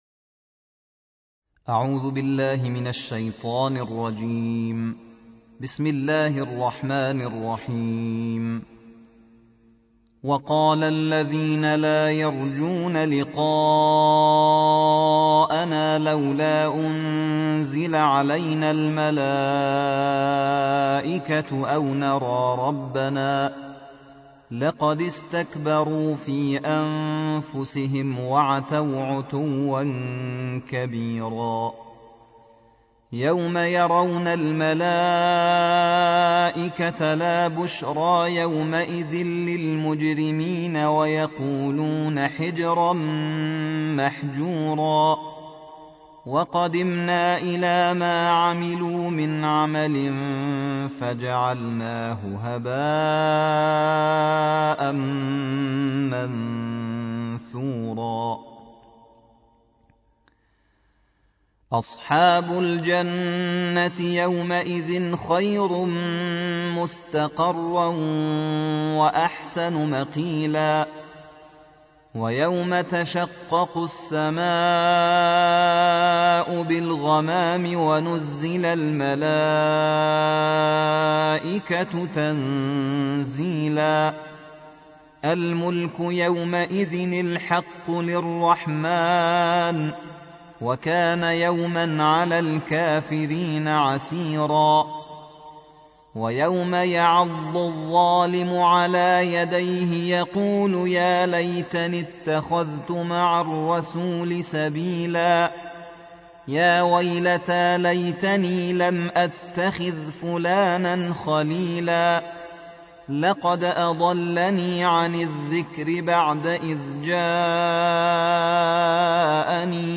الجزء التاسع عشر / القارئ
القرآن الكريم